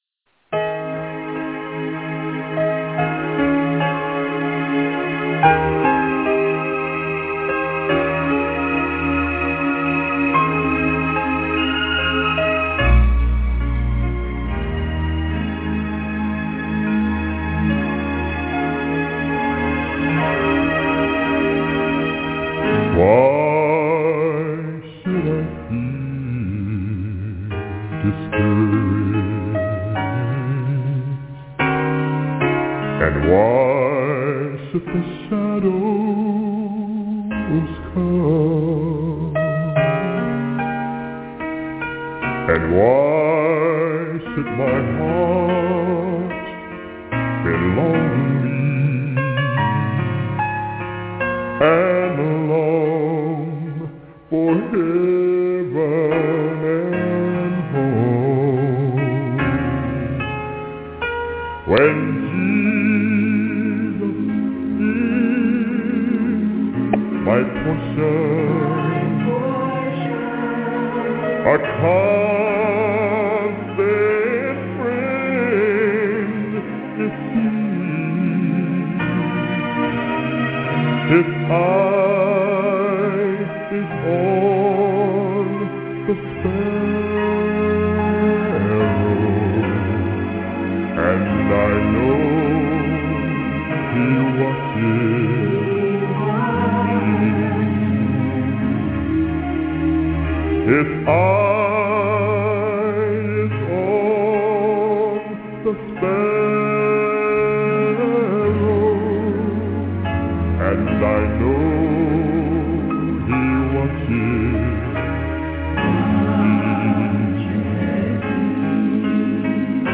This study is a discussion about the Feasts of Leviticus 23, whether or not they were nailed to the Cross of Y'shuah, or that they were survived throughout the centuries by the true and obedient Christians in an unbroken chain.